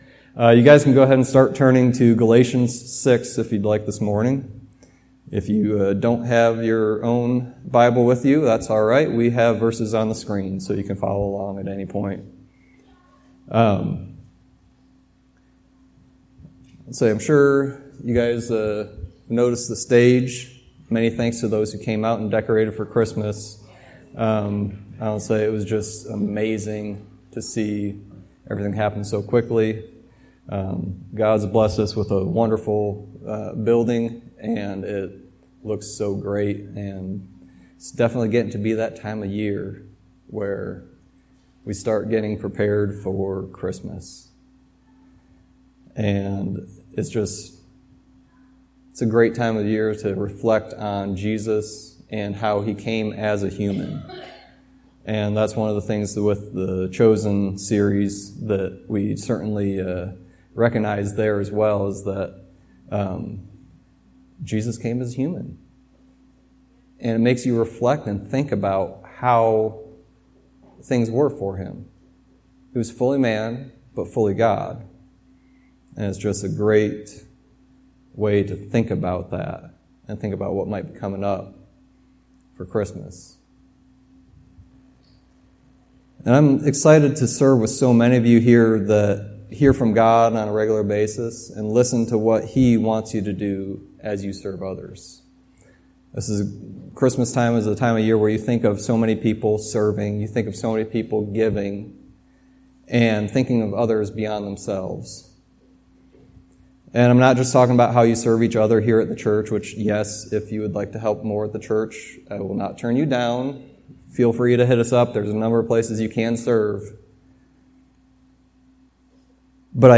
Sermon messages available online.
Galatians 6:7-10 Service Type: Sunday Teaching This time of year